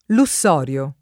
luSS0rLo] pers. m. — d’uso regionale (sardo) — San Lussorio l’antica chiesa sul luogo di martirio del santo, presso Fordongianus; e così altre chiese e vari centri abitati (Sard.); ma Santu Lussurgiu il comune nel Montiferru (Sard.), e San Rossore il parco e la tenuta tra le bocche d’Arno e di Serchio (Tosc.)